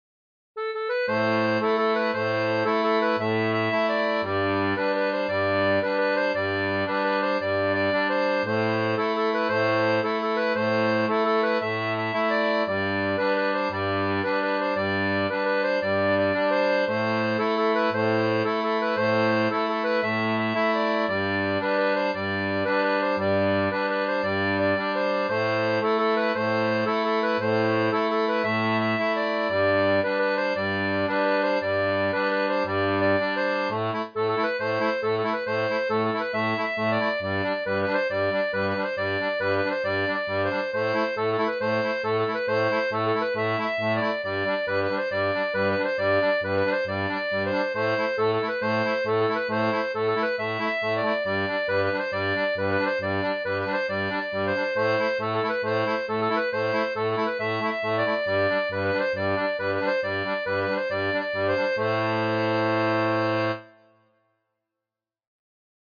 • Une tablature pour diato 2 rangs (transposée en A)
Chanson française